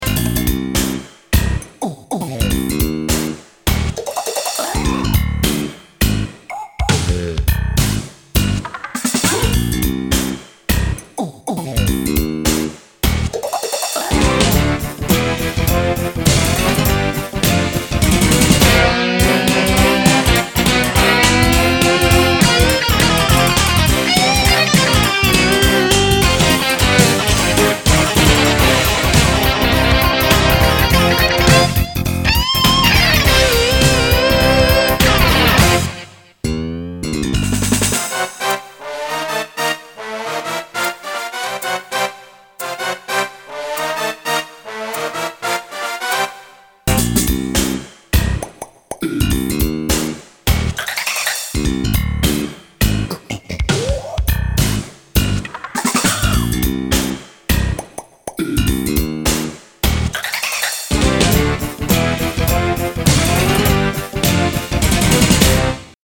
Vocal Effects and Guitar Solo